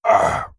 Vampire_Hurt1.wav